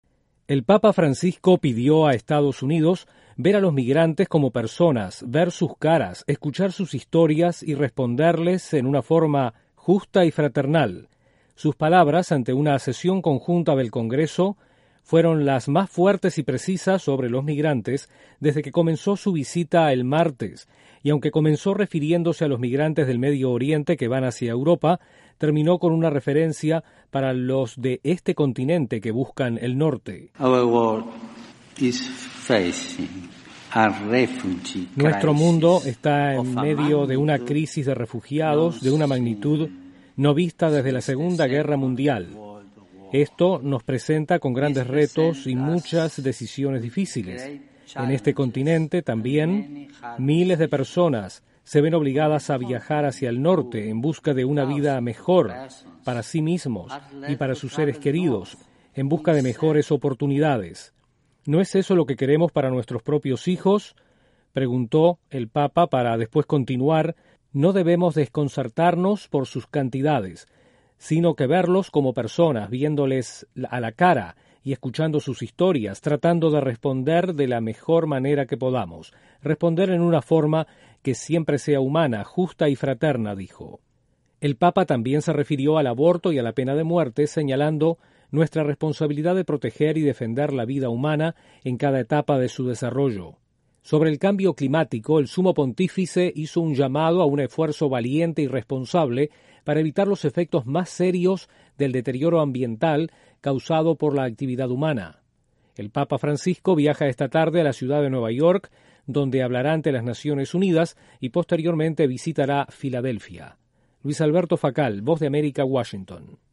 El papa Francisco abogó por un trato justo a los migrantes durante un discurso ante el Congreso de Estados Unidos, este jueves. Desde la Voz de América en Washington informa